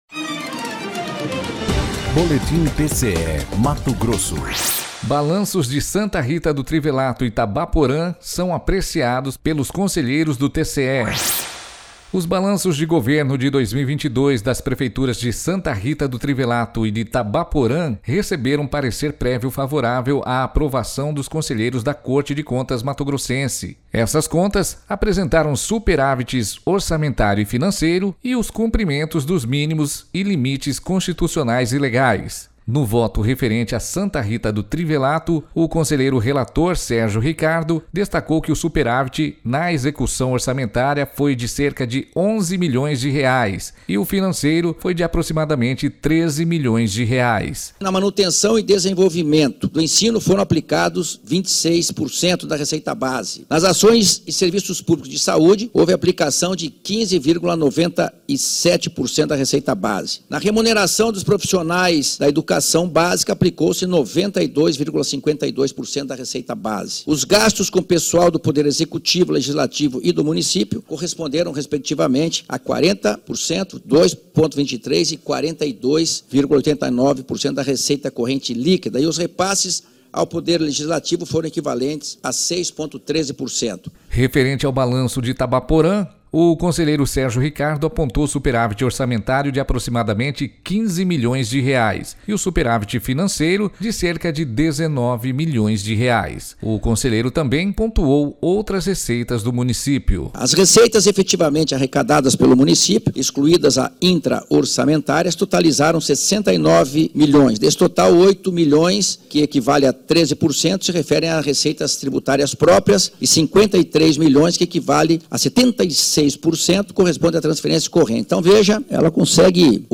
Sonora: Sérgio Ricardo – conselheiro do TCE-MT